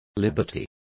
Complete with pronunciation of the translation of liberties.